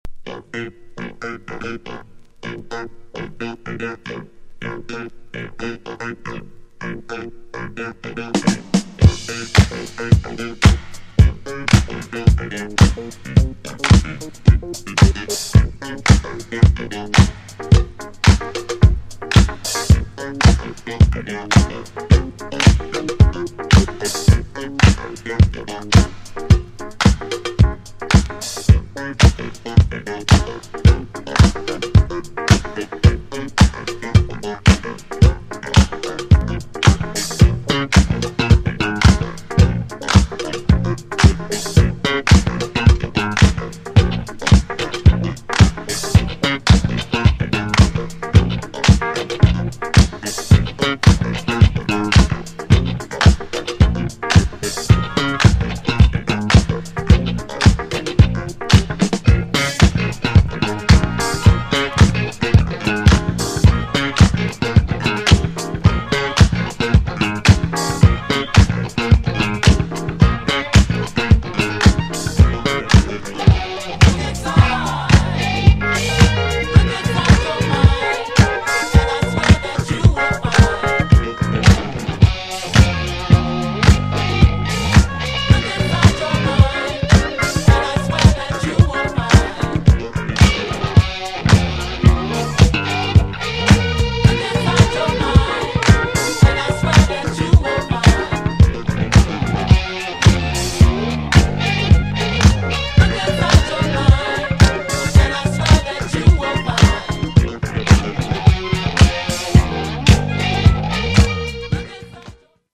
PHILLYぽくないヘビーなギターと固いベース。そこにスペーシーなシンセがのるイタロぽいGROOVEのFUNK!!
GENRE Dance Classic
BPM 116〜120BPM